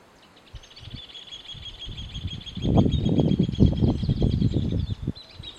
Rufous Hornero (Furnarius rufus)
Country: Argentina
Location or protected area: Santa María
Condition: Wild
Certainty: Recorded vocal